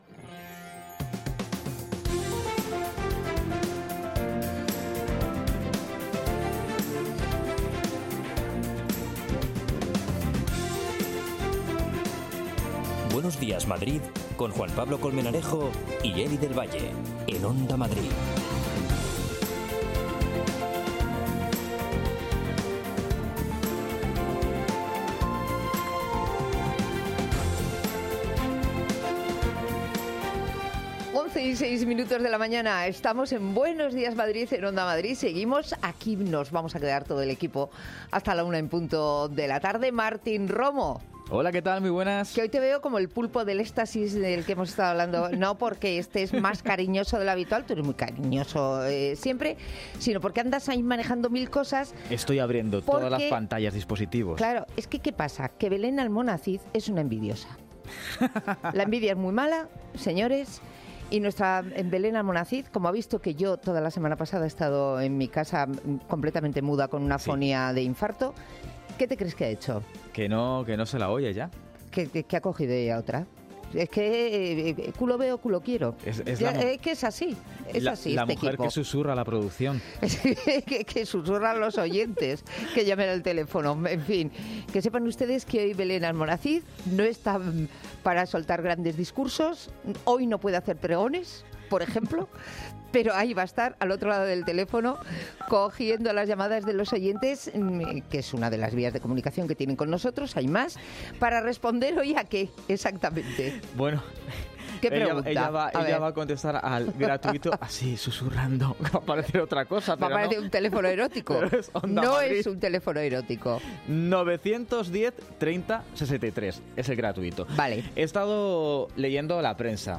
Preguntamos a nuestros oyentes por sus manías a la hora de dormir y los trucos para conciliar el sueño. Nuestra unidad móvil está en el Teatro Coliseum para comprobar cómo van los últimos ensayos del musical, Anastasia.